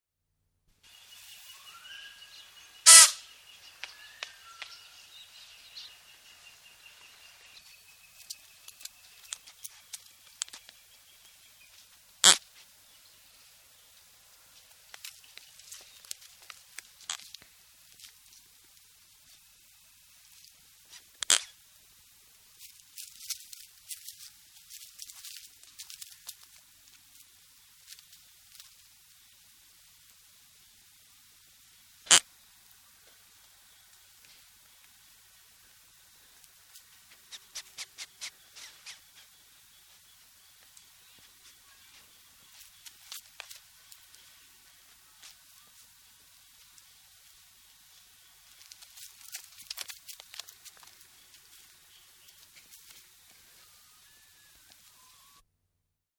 Антилопа-прыгун издает характерный звук в дикой природе